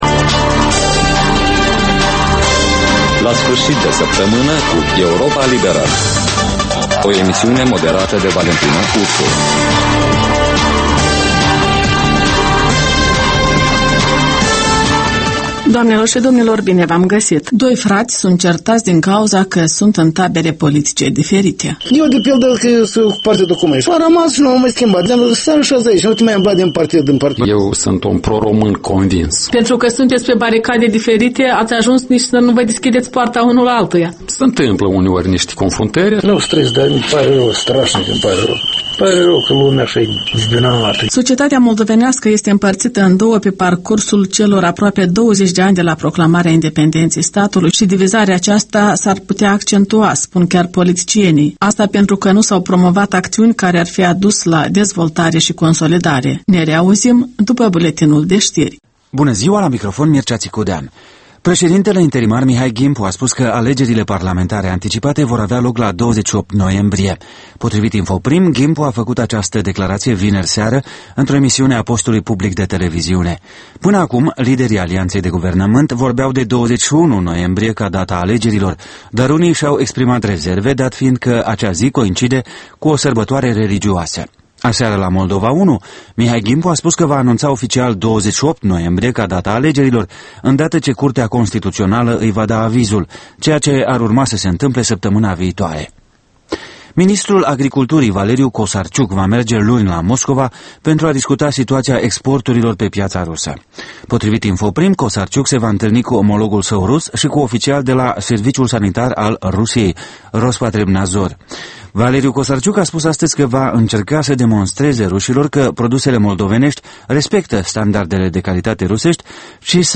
In fiecare sîmbătă, un invitat al Europei libere semneaza "Jurnalul săptămînal".